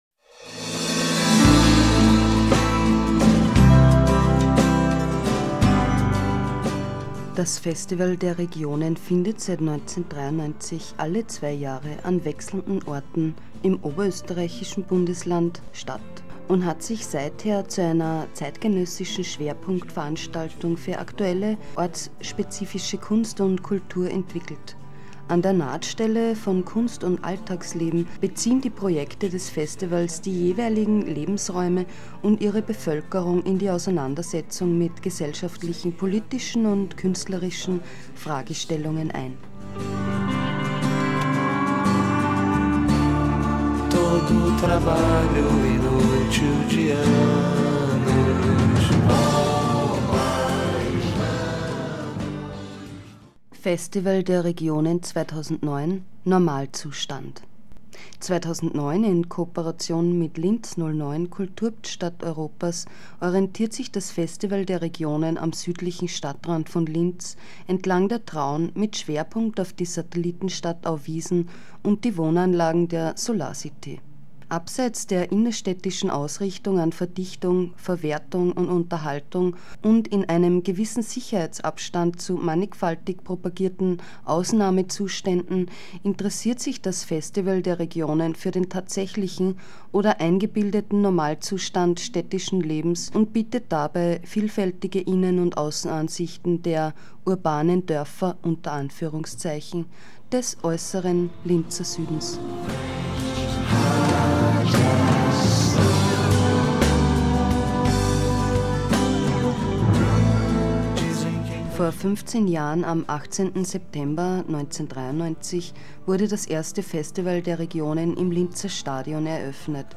Im Beitrag zu hören sind Mitschnitte von der Pressekonferenz, die am 6. Oktober 2008 in Linz stattgefunden hat.